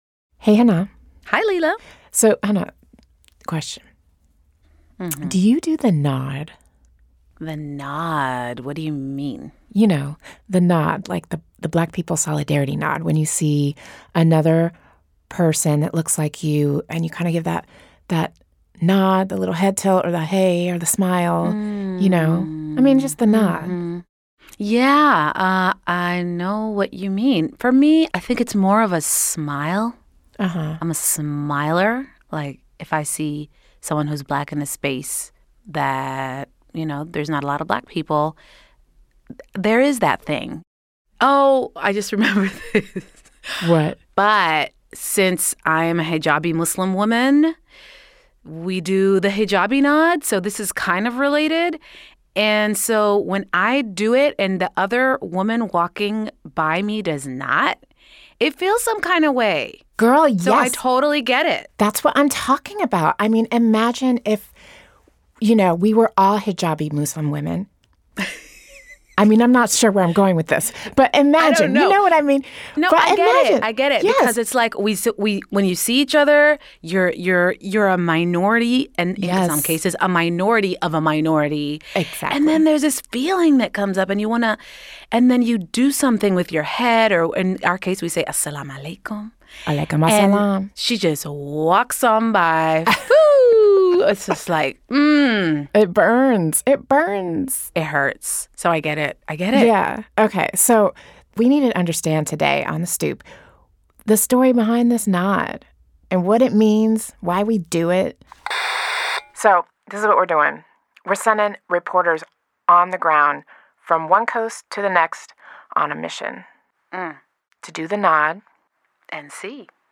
The project was selected for NPR's first-ever Storytelling Workshop.